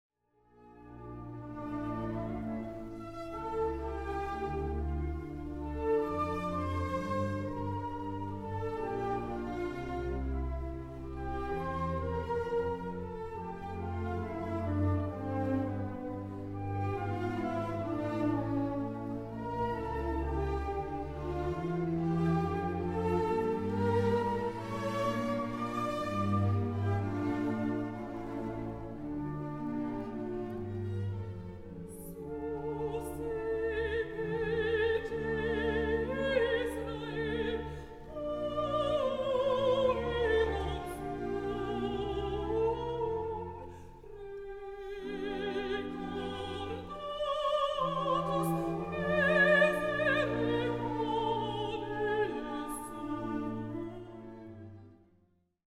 Many people would say it’s a violin “disguised” into a piano, but I rather see it as coming from the human voice, pretty much owing its heritage to a baroque aria, like this one by Carl Philip Emmanuel Bach:
cpebachsong.mp3